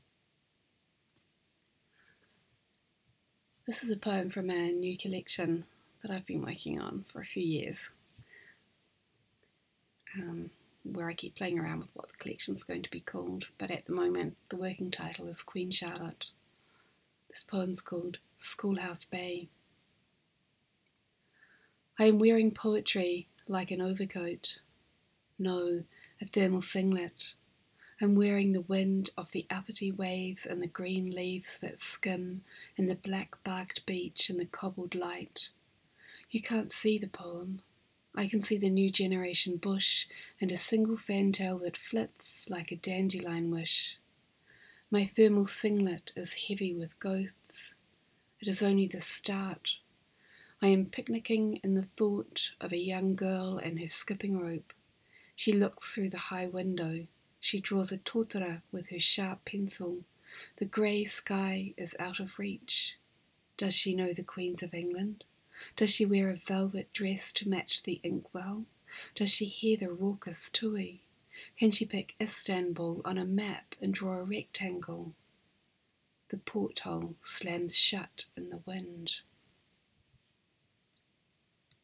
And here is me reading ‘School House Bay’: